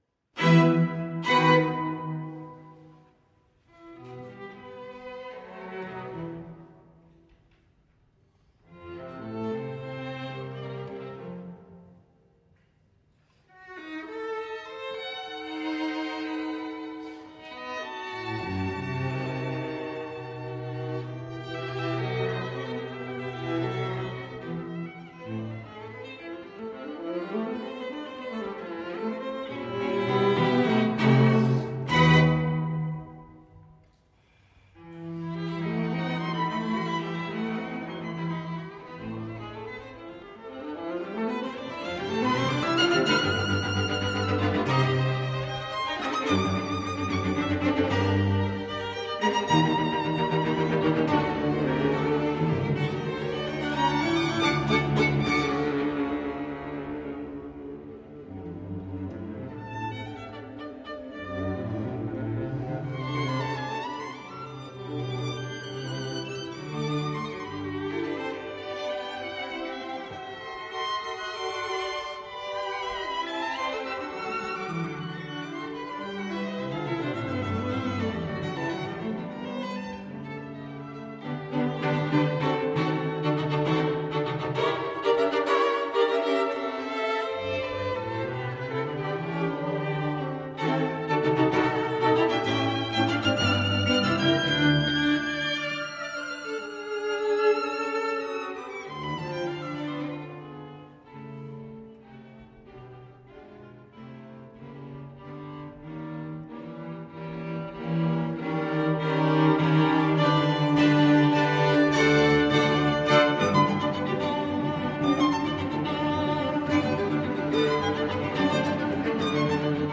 String Quartet
Style: Classical
Attribution: Audio: Borromeo String Quartet (String Quartet).
string-quartet-8-op-59-2.mp3